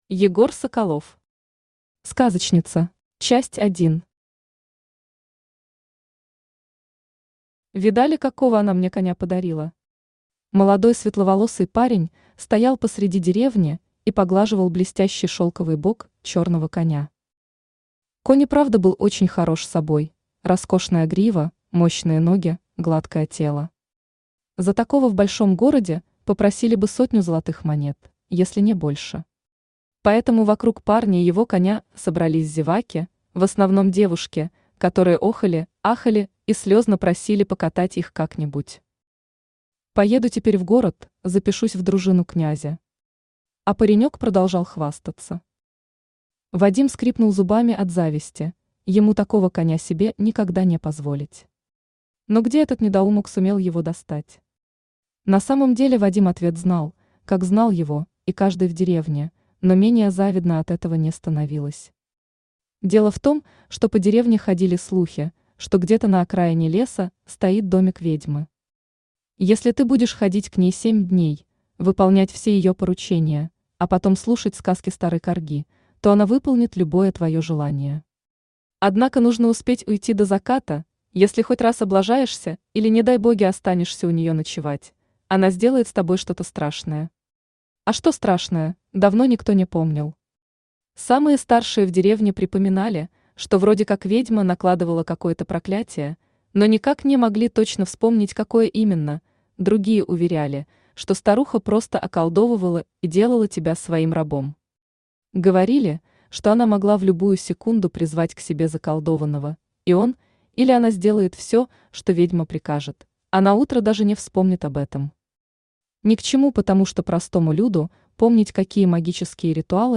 Aудиокнига Сказочница Автор Егор Соколов Читает аудиокнигу Авточтец ЛитРес.